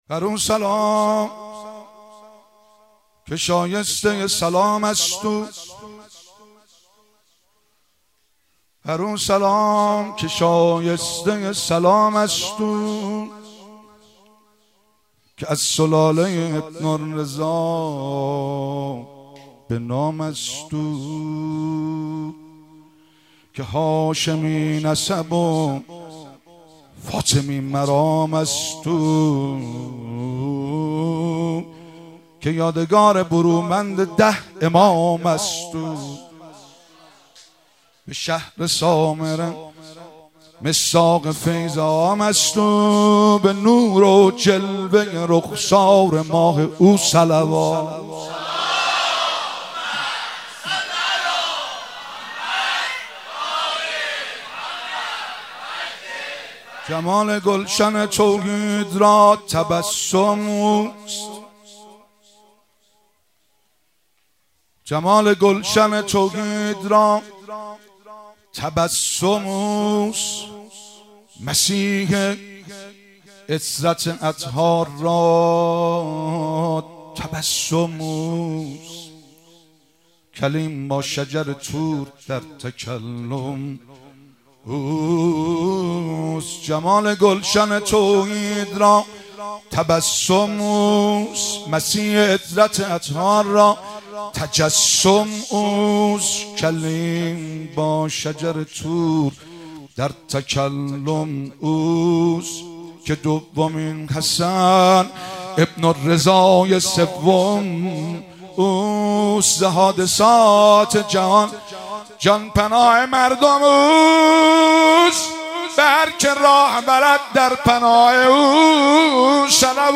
جشن میلاد امام حسن عسکری (ع)- آبان 1401